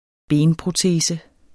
Udtale [ ˈbeːnpʁoˈteːsə ]